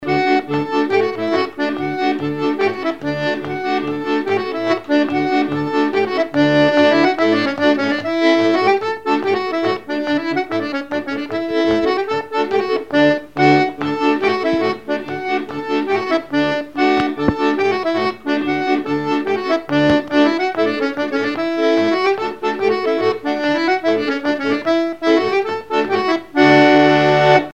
Chants brefs - A danser
danse : polka piquée
instrumentaux à l'accordéon diatonique
Pièce musicale inédite